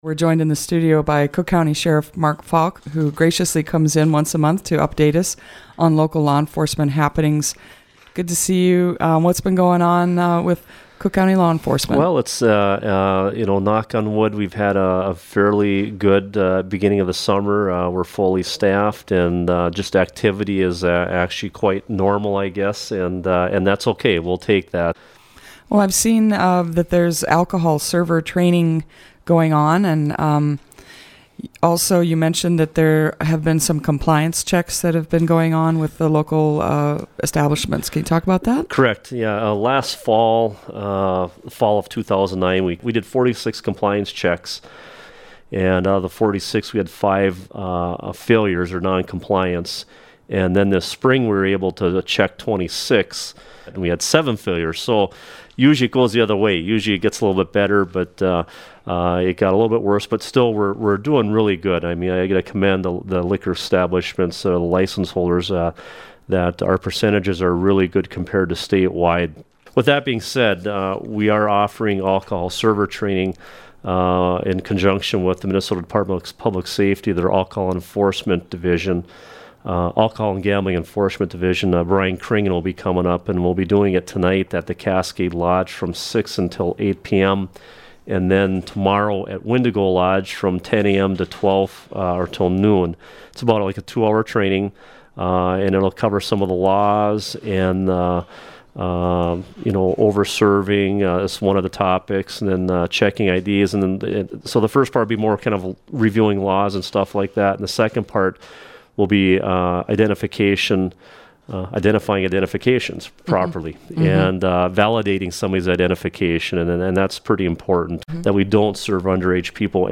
Cook County Sheriff Mark Falk visits WTIP each month to talk about local law enforcement happenings.